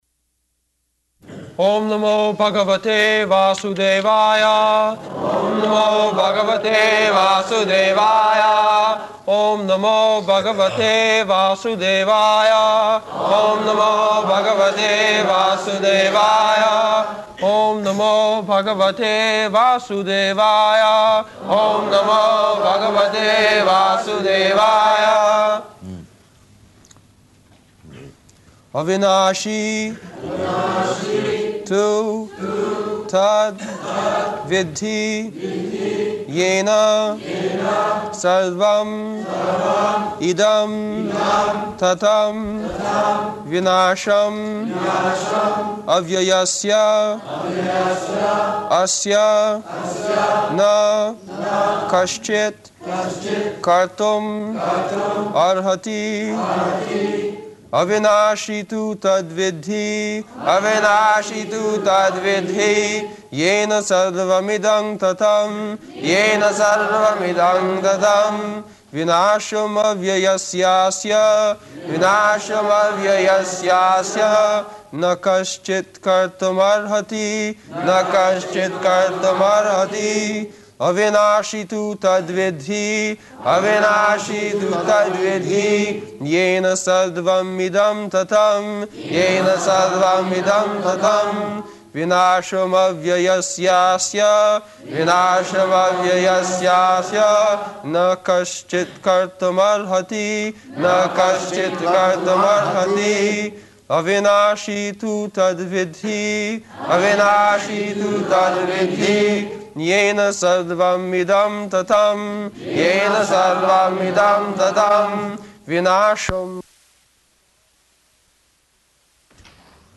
August 23rd 1973 Location: London Audio file